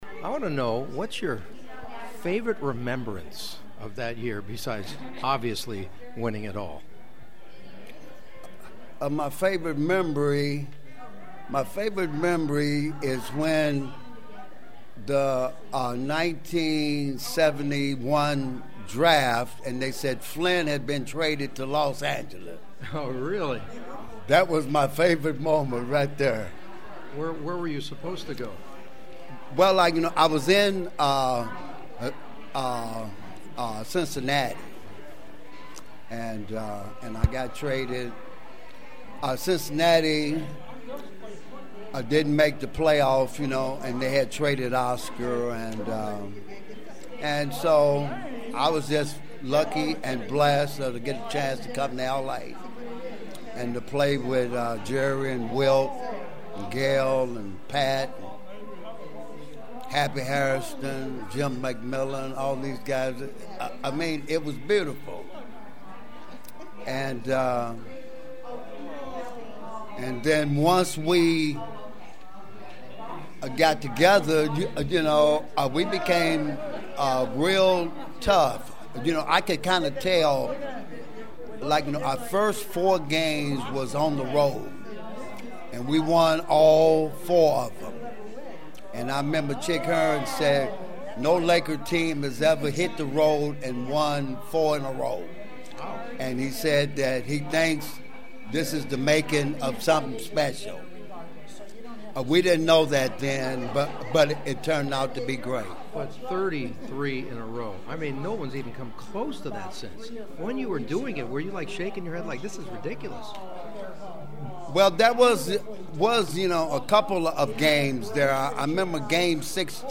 But for Flynn Robinson (as you’ll hear in our sitdown chat) he was most excited because he got to play for this storied franchise and in this special city.